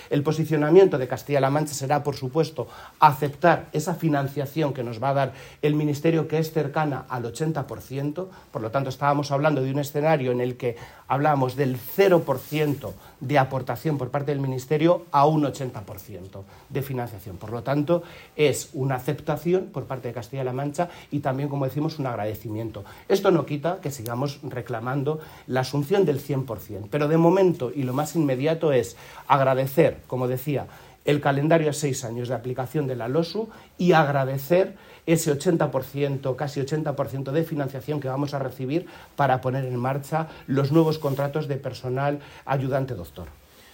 >> Así lo ha dicho el consejero de Educación, Cultura y Deportes a preguntas de los medios de comunicación
Así lo ha indicado Amador Pastor a preguntas de los medios de comunicación hoy durante la inauguración de la Escuela Infantil de Santa Olalla, un acto presidido por el presidente regional, Emiliano García-Page.